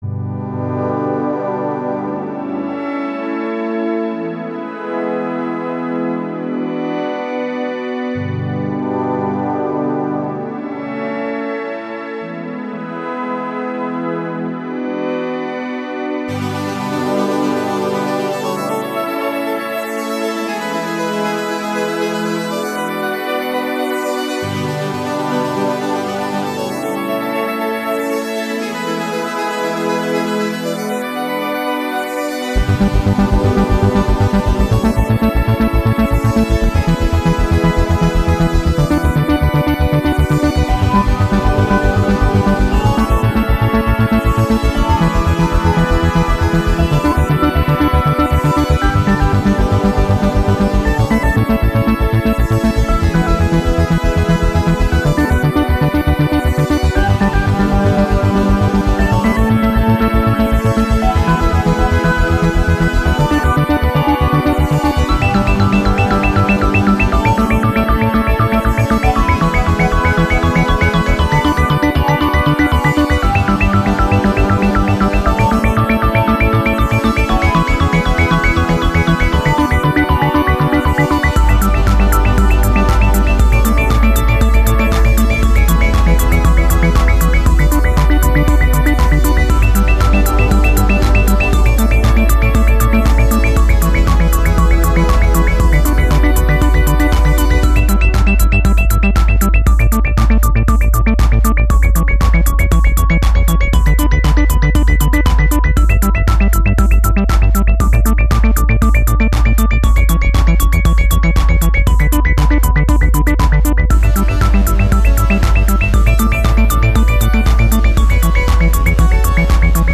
80's electro[Unfinished]
Seems a bit more modern then an 80's piece.
Sounds really good...